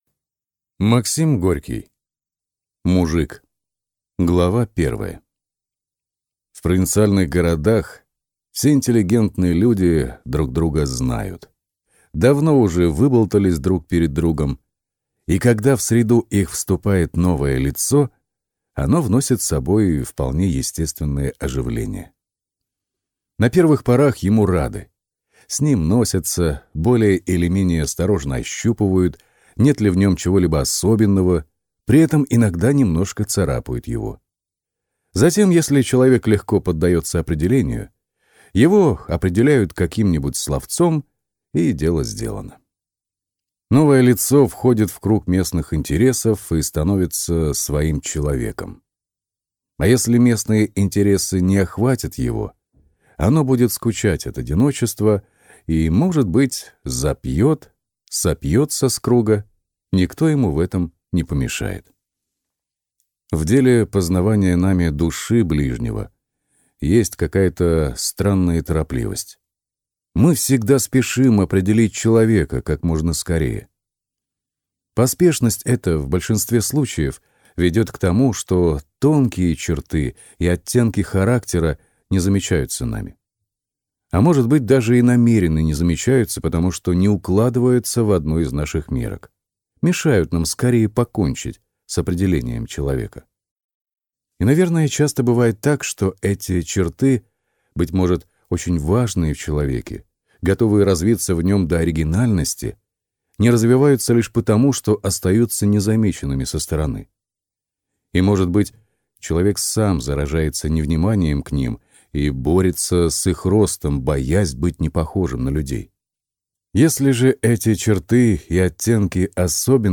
Аудиокнига Мужик | Библиотека аудиокниг